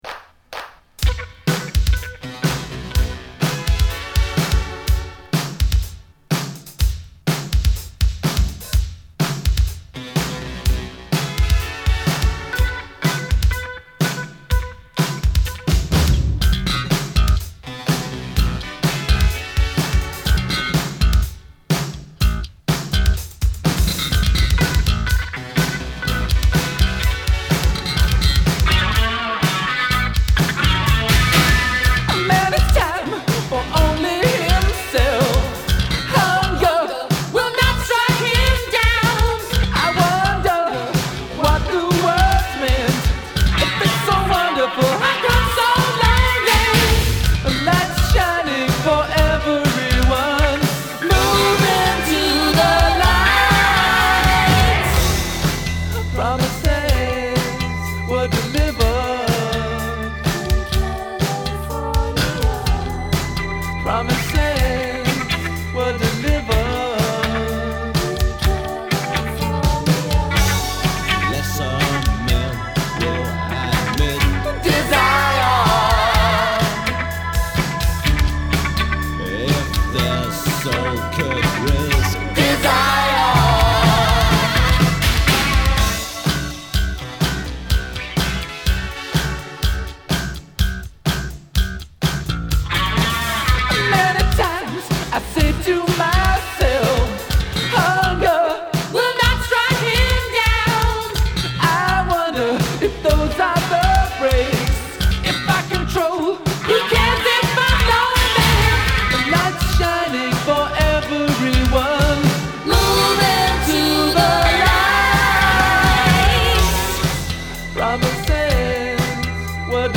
I ripped it from my original vinyl copy this very morning.